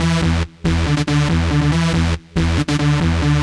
Index of /musicradar/future-rave-samples/140bpm